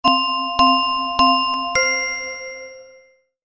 جلوه های صوتی
دانلود صدای ساعت 7 از ساعد نیوز با لینک مستقیم و کیفیت بالا